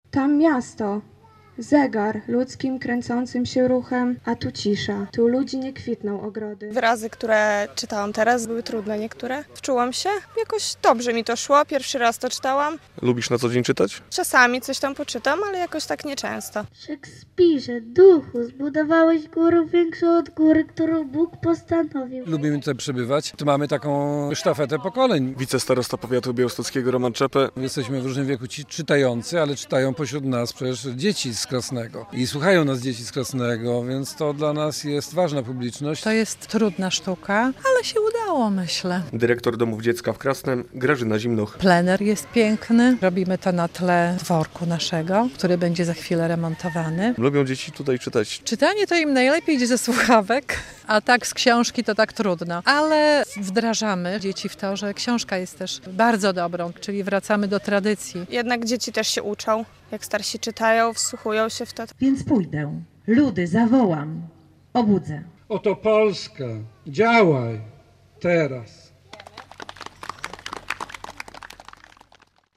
W plenerze w Krasnem rozbrzmiały fragmenty "Kordiana" Juliusza Słowackiego.
Jak mówi wicestarosta Roman Czepe, wspólne czytanie to istna sztafeta pokoleń.
Samo odczytanie miało miejsce w plenerze, przed dworkiem, który niebawem przejdzie remont.